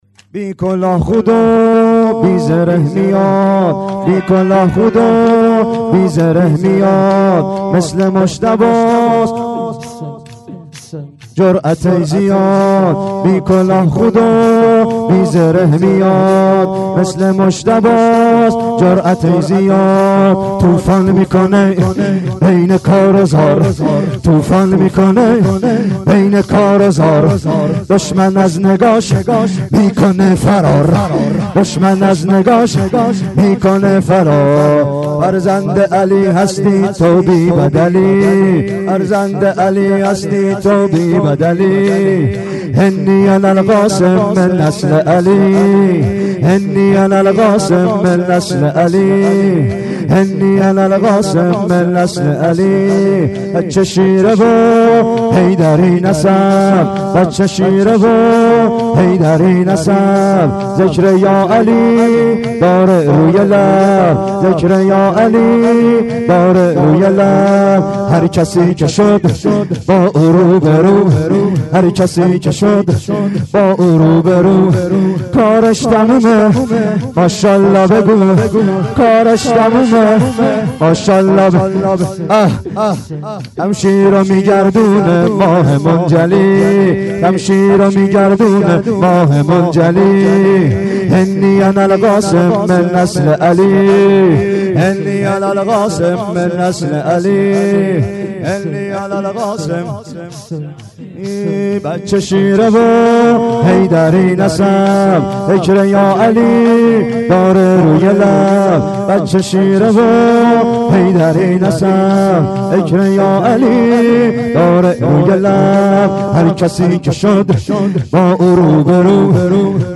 گلچین شورهای محرم 93
شور شب ششم : بی کلاه خود و بی زره میاد(زیبا)